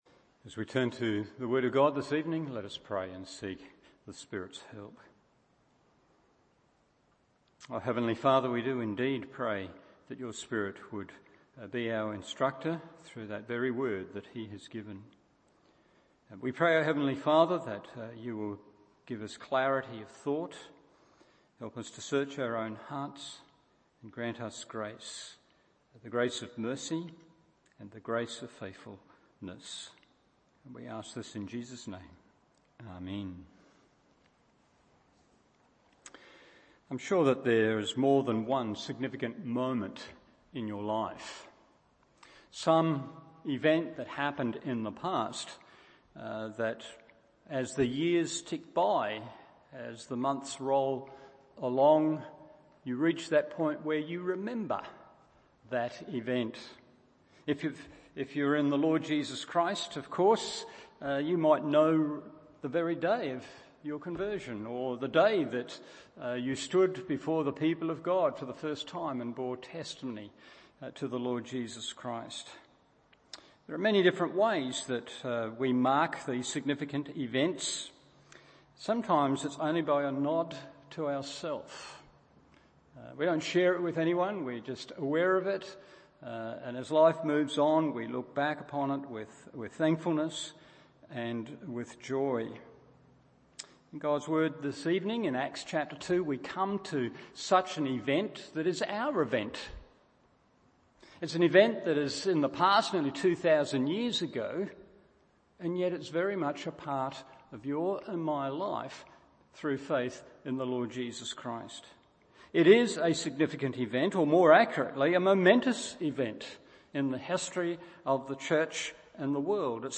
Evening Service Acts 2:1-13 1. In signs 2. In sameness 3.